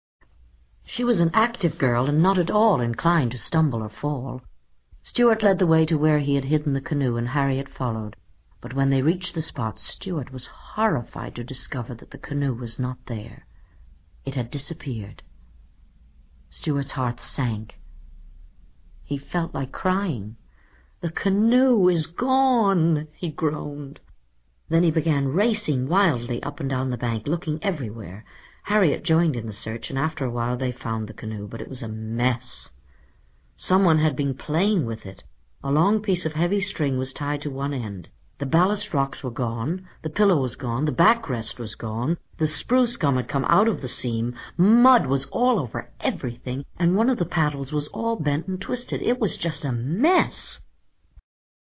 在线英语听力室精灵鼠小弟 第82期:被毁坏的独木舟的听力文件下载, 《精灵鼠小弟》是双语有声读物下面的子栏目，是学习英语，提高英语成绩的极好素材。